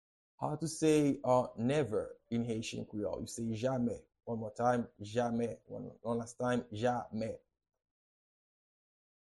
Pronunciation and Transcript:
How-to-say-Never-in-Haitian-Creole-Jame-pronunciation.mp3